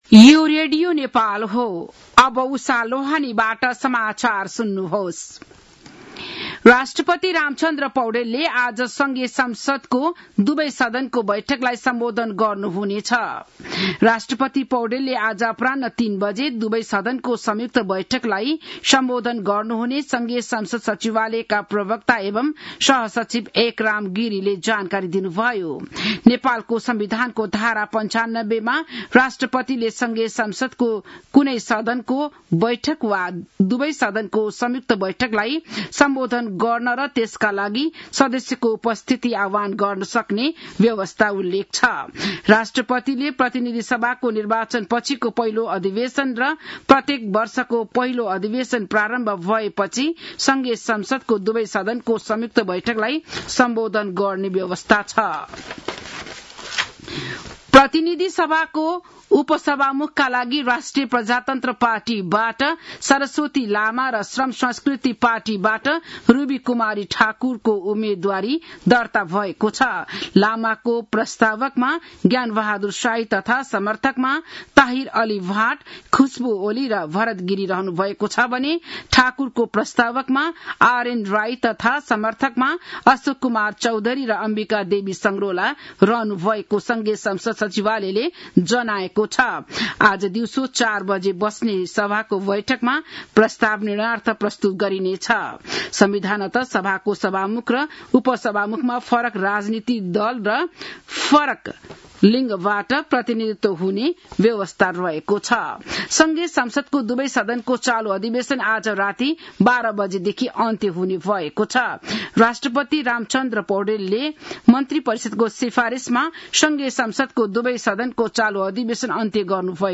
बिहान १० बजेको नेपाली समाचार : २७ चैत , २०८२